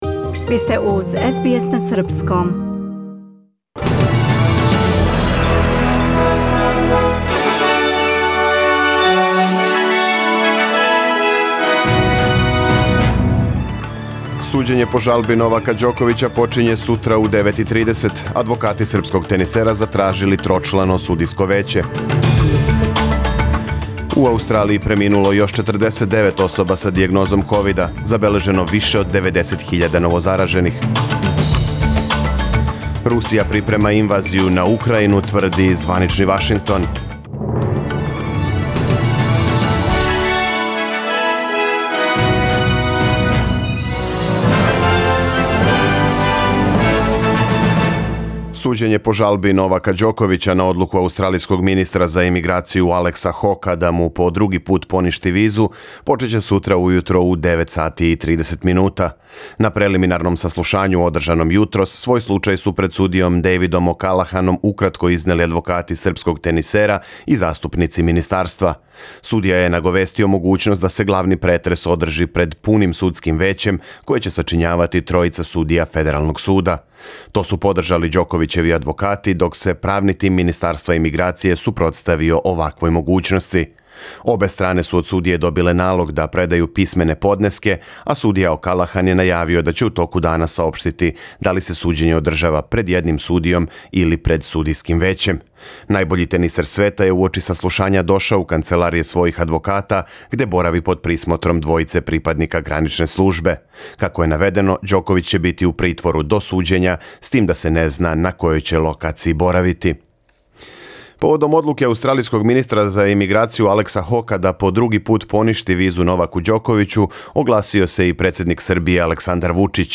Преглед вести за 15. јануар 2022. године